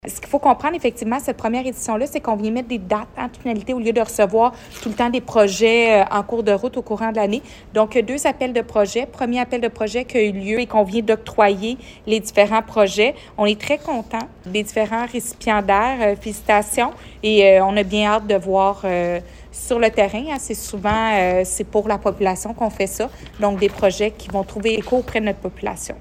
Julie Bourdon, mairesse de Granby.